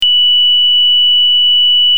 出力波形　５ビットＤ／Ａコンバータ疑似サイン波
出力信号には相当高調波成分が含まれている。
本キットのサンプル音を下記表に掲載します。
38　（約2944Hz）